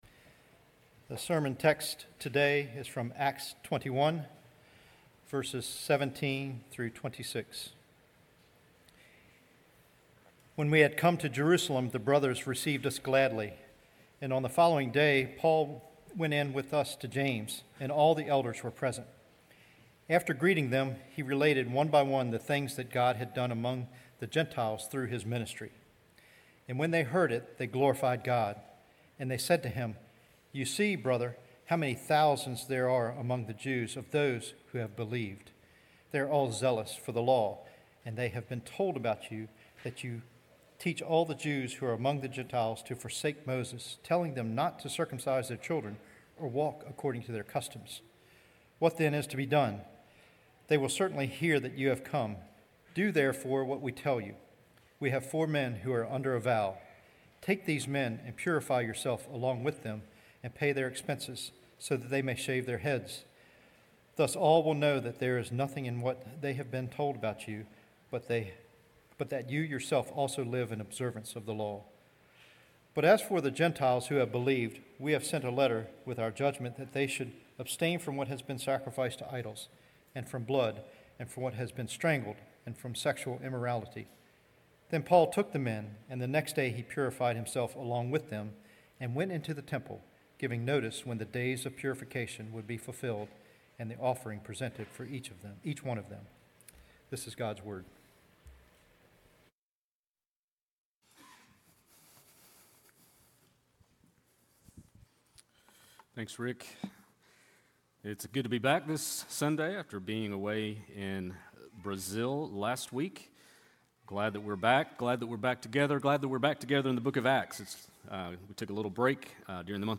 sermon9.8.25.mp3